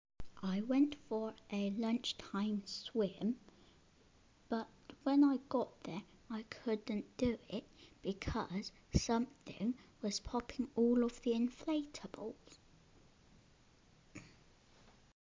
Listen to the swimmer
sports-centre-swimmer.mp3